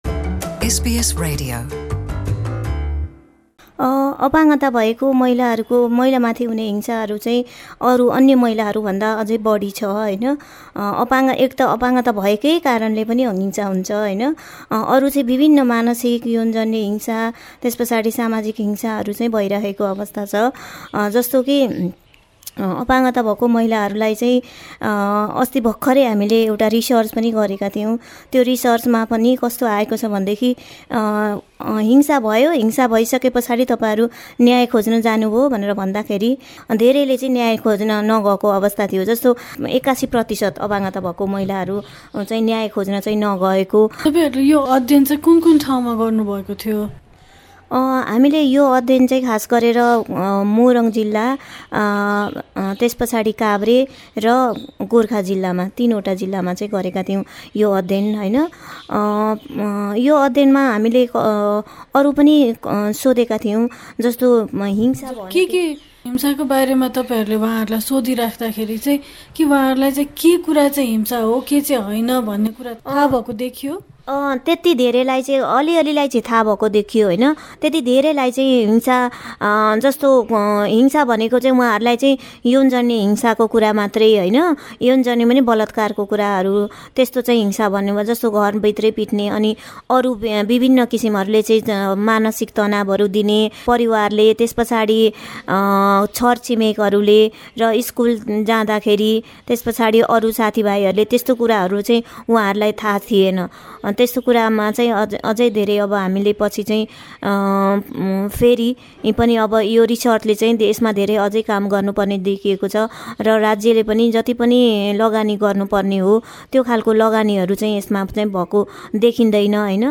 हाम्रो पुरा कुराकानी सुन्न माथि रहेको मिडिया प्लेयरमा प्ले बटन थिच्नुहोस् अपाङ्गता भएका महिला माथि हुने हिंसाको स्थिति कस्तो छ?